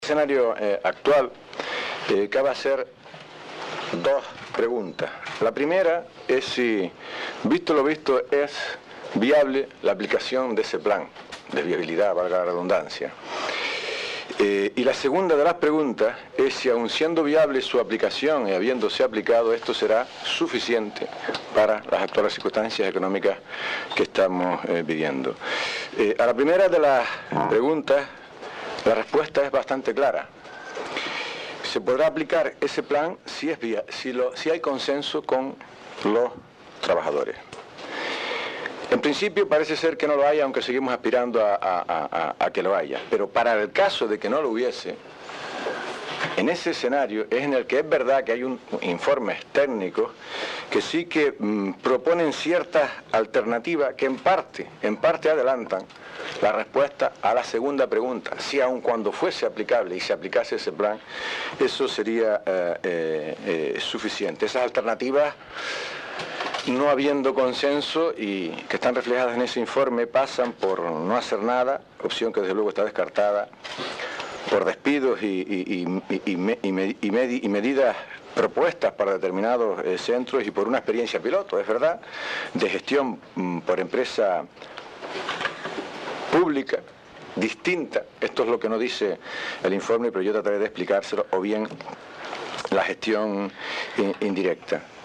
En la rueda de prensa en la que el Grupo de Gobierno presentó públicamente ese documento, San Ginés puntualizó además que el plan de viabilidad no contenpla ningún despido, pero sólo se pondrá en marcha si es aceptado por los trabajadores, al tiempo que expresó su escepticismo acerca de que ello ocurra.